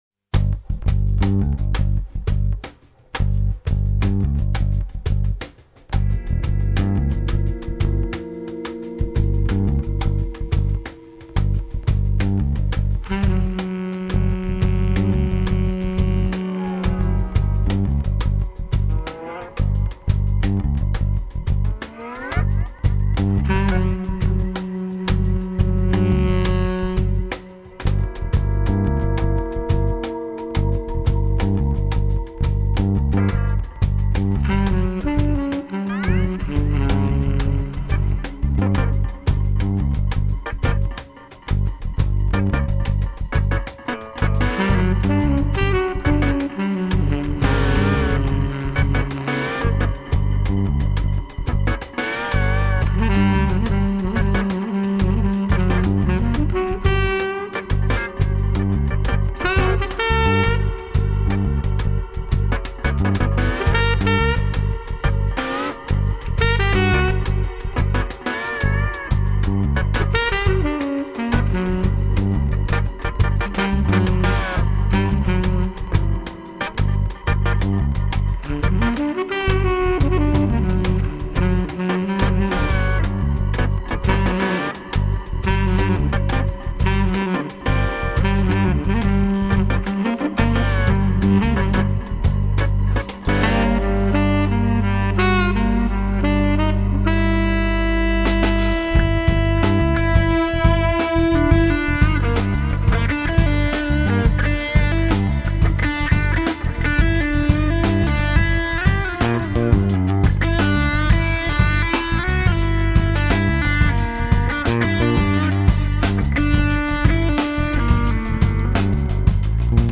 guitar
drums